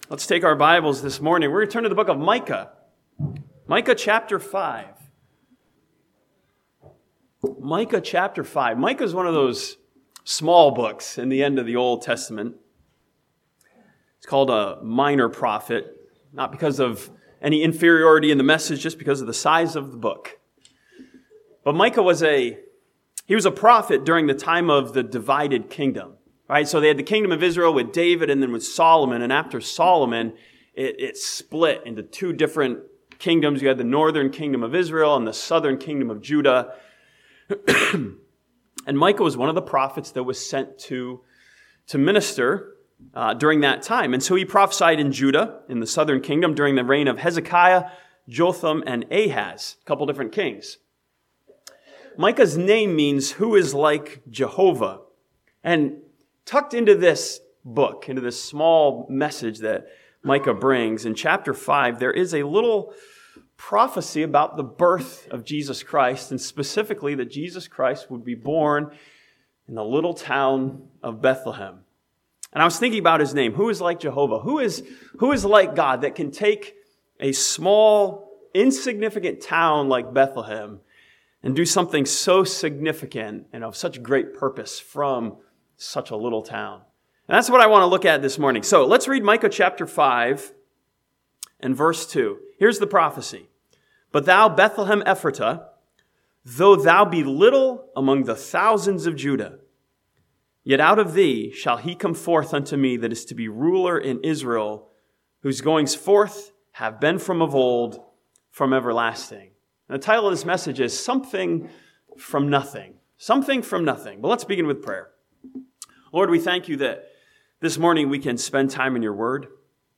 This sermon from Micah 5:2 studies the prophecy of Jesus' birth in Bethlehem as proof that God can bring something from nothing.